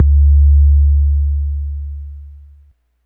bass02.wav